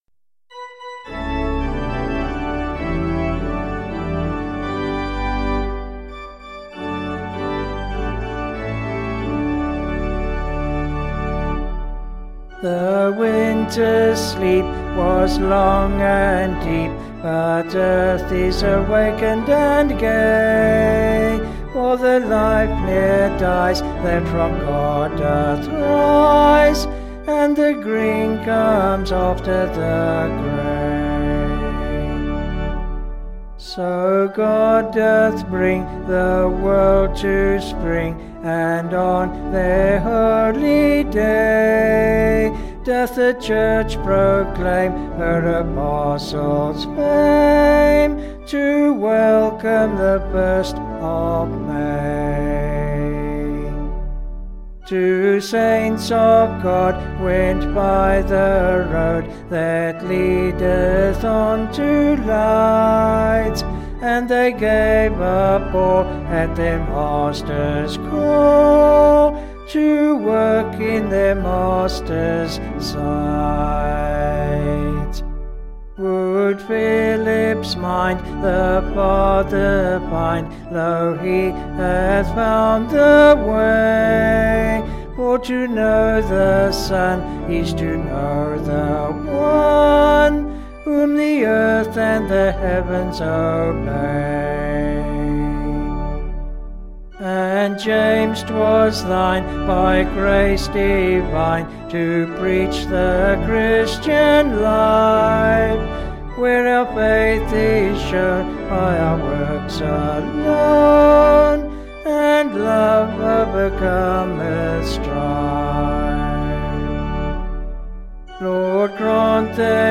Vocals and Organ   263.7kb Sung Lyrics